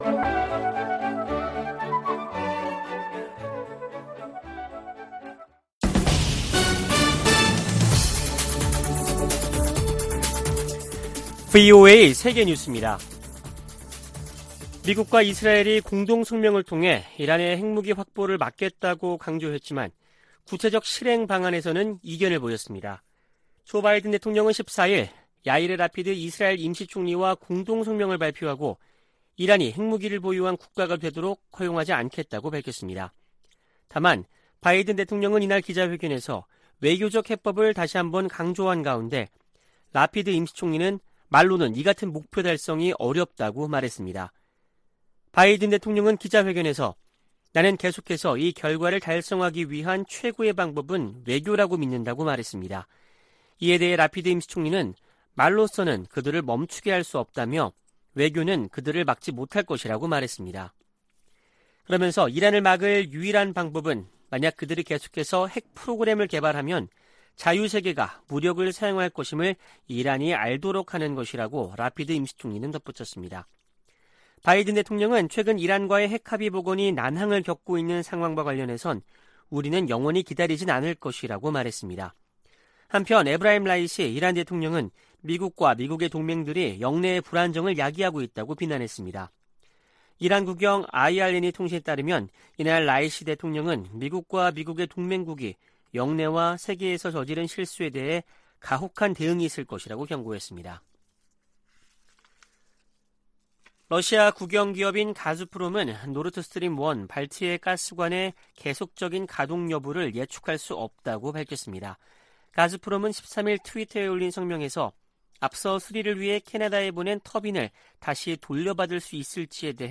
VOA 한국어 아침 뉴스 프로그램 '워싱턴 뉴스 광장' 2022년 7월 15일 방송입니다. 미국과 한국 공군이 처음으로 F-35A 스텔스 전투기가 참여하는 연합비행훈련을 실시했습니다. 미한동맹이 다영역 환경에서 작전을 수행할 수 있는 동맹으로 탈바꿈해야 한다고 주한미군사령관이 제언했습니다. 북한이 핵무기를 위한 플루토늄을 생산하기 위해 영변 핵시설 내 원자로를 계속 가동하고 있을 가능성이 높다고 전 국제원자력기구(IAEA) 사무차장이 밝혔습니다.